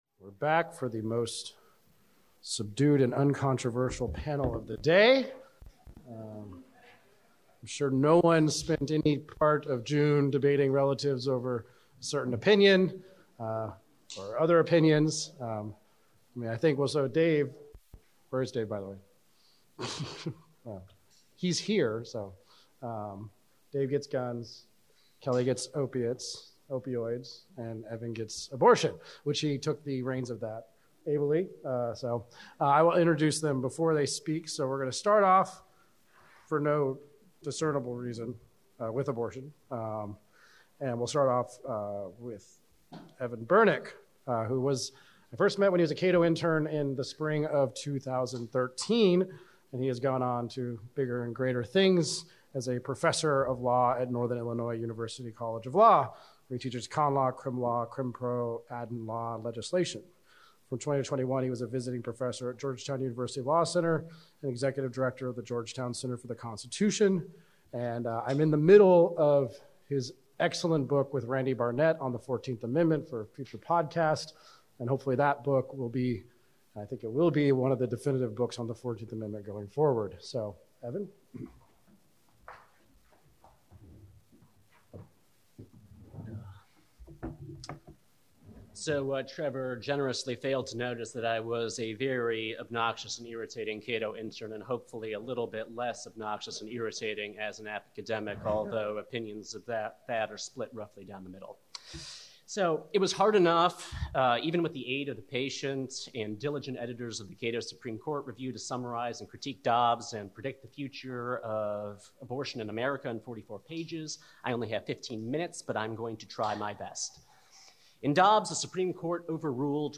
Cato’s annual Constitution Day symposium marks the day in 1787 that the Constitutional Convention finished drafting the U.S. Constitution. We celebrate that event each year with the release of the new issue of the Cato Supreme Court Review and with a day‐long symposium featuring noted scholars discussing the recently concluded Supreme Court term and the important cases coming up.